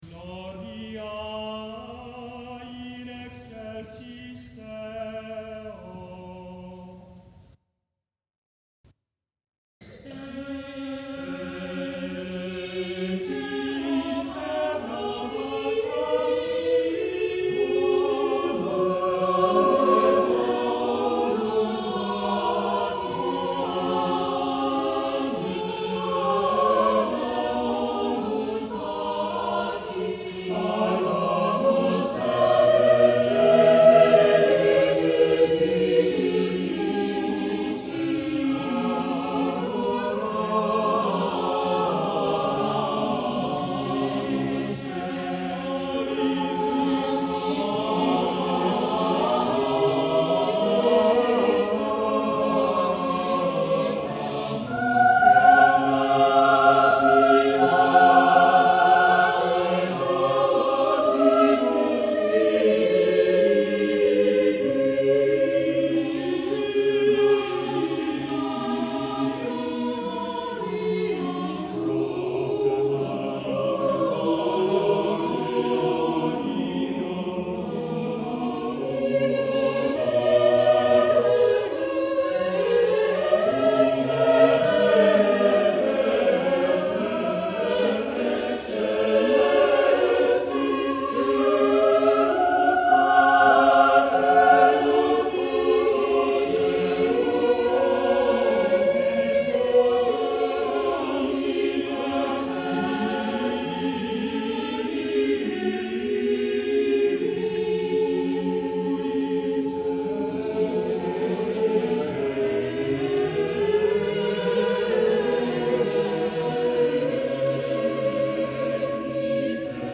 The few pieces heard here attest to his mastery of vocal polyphony as practiced especially by Cristobal de Morales in Spain, whose works are found in many Mexican sources.